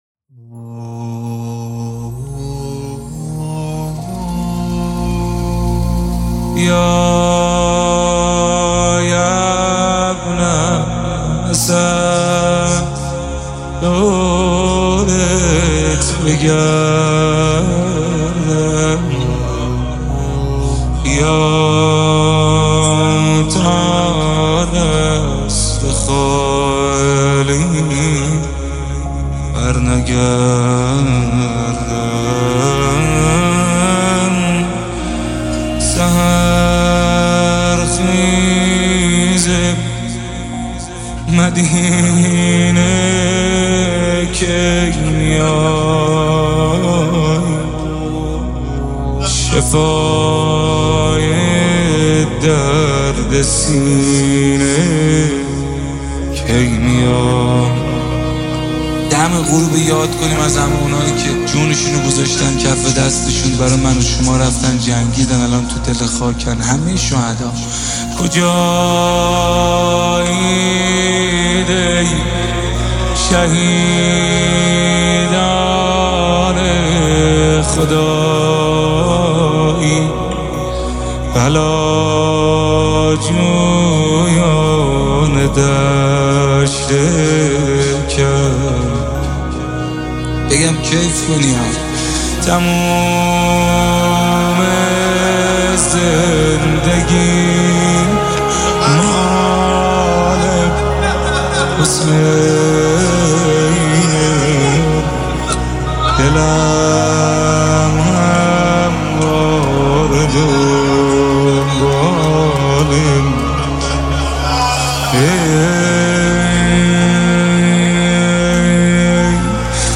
مداحی امام زمان